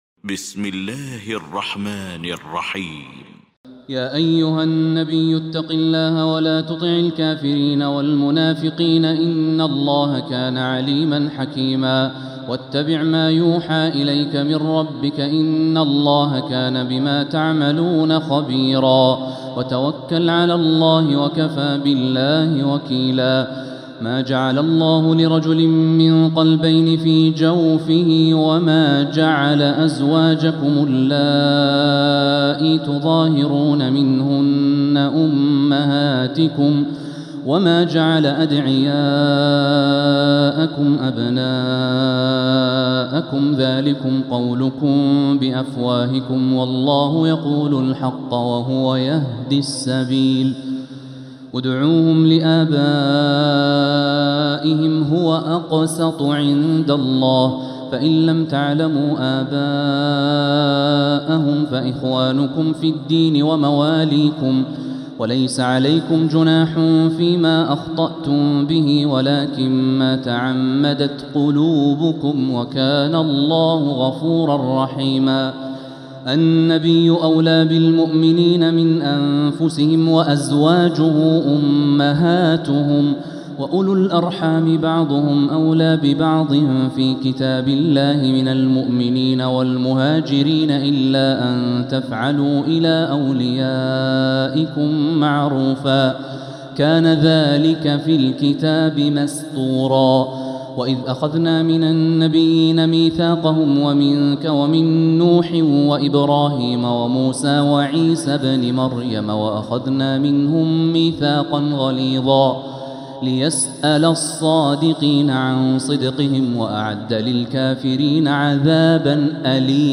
سورة الأحزاب Surat Al-Ahzab > مصحف تراويح الحرم المكي عام 1446هـ > المصحف - تلاوات الحرمين